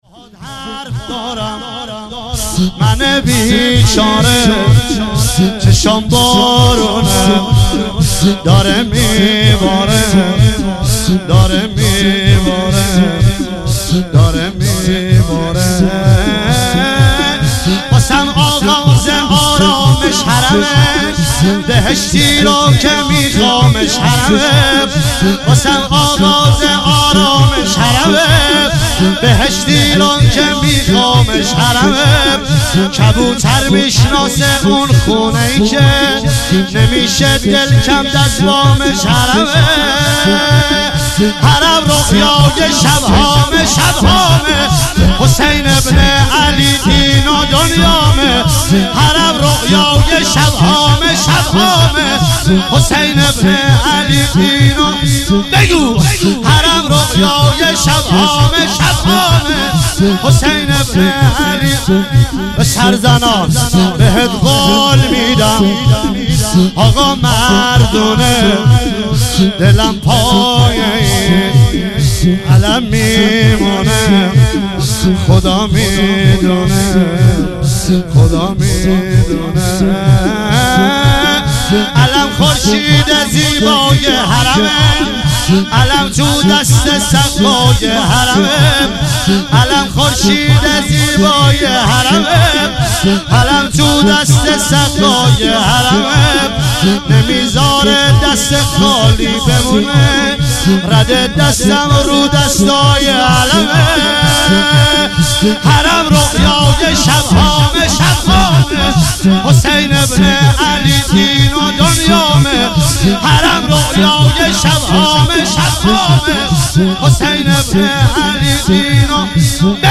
شب ششم محرم 97 - شور - باهات حرف دارم منه بی چاره